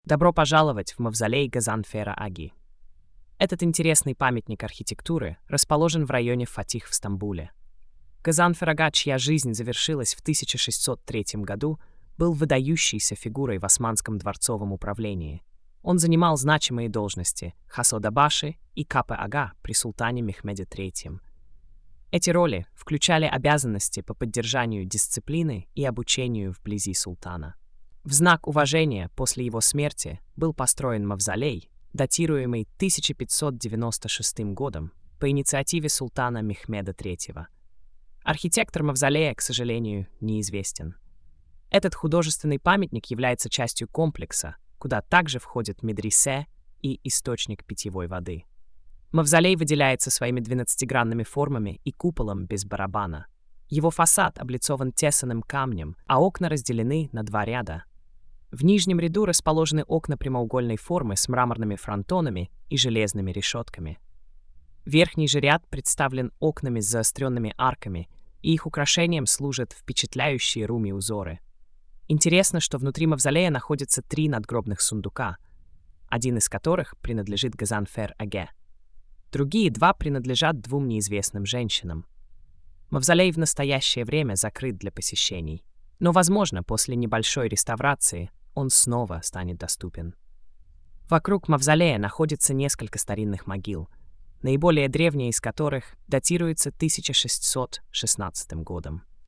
Аудиоповествование: